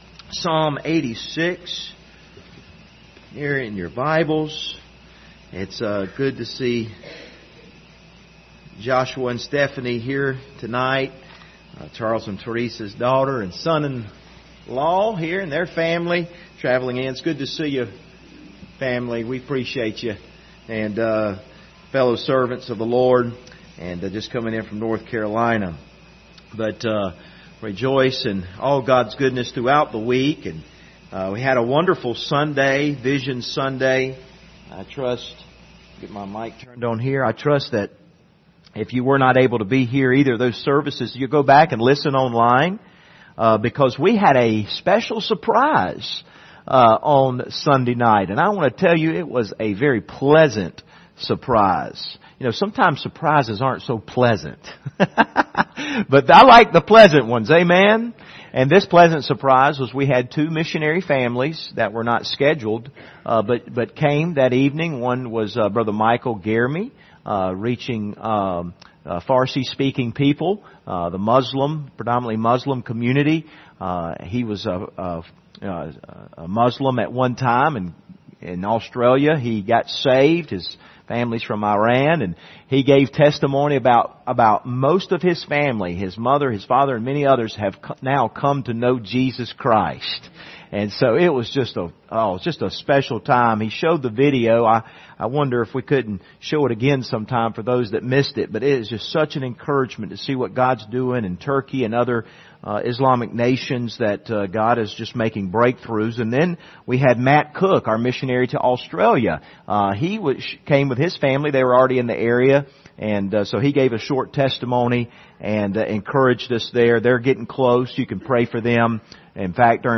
Psalm 86:1-7 Service Type: Wednesday Evening View the video on Facebook « He Must Increase